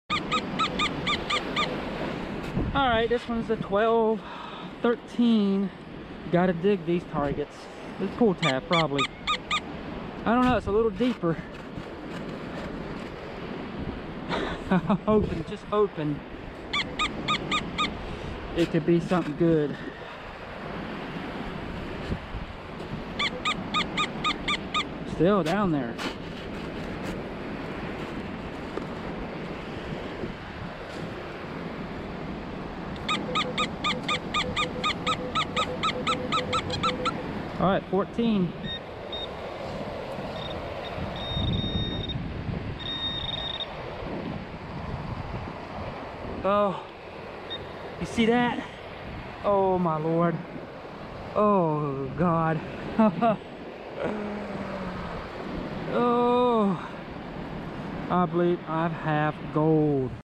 Finding big gold on the beach while I was metal detecting in search of lost treasure digging around in the sand looking for what people have lost